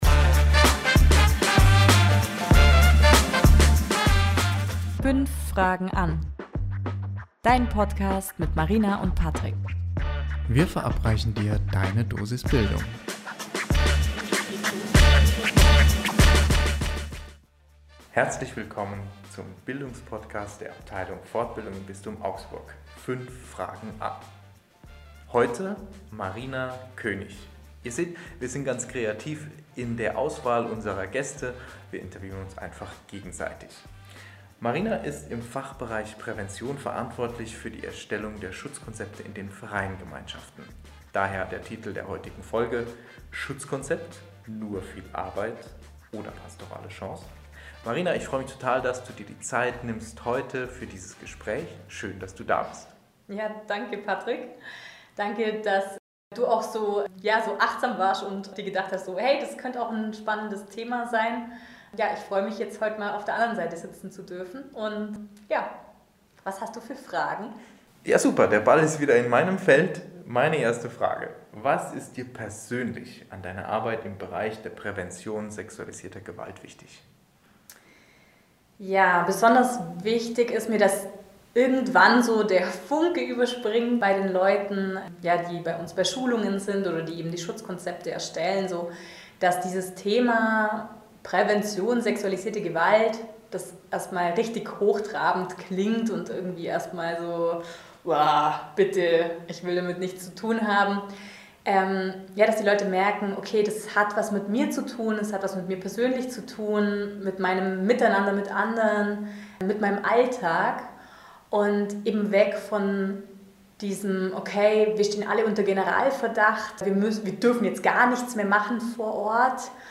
- Wir waren noch mit unserem alten Mikro unterwegs - die Qualität wird besser, versprochen ;) !